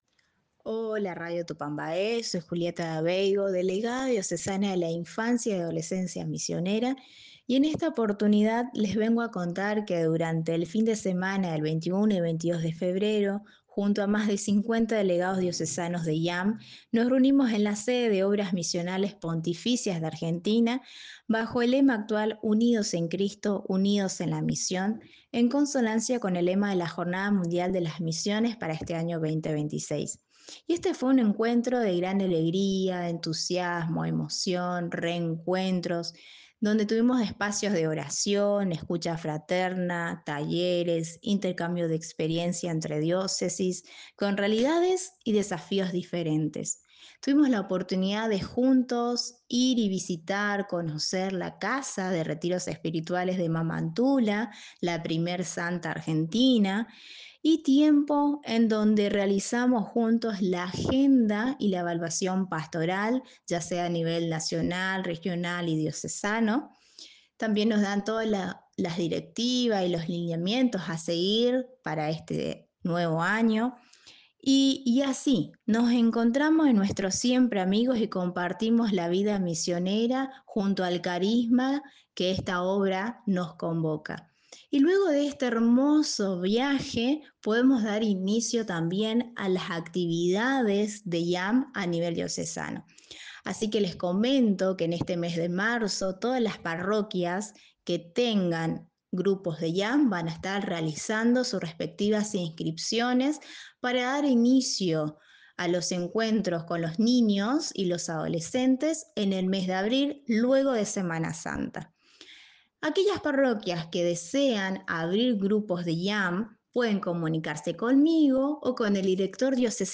En diálogo exclusivo con la Redacción de Tupambaé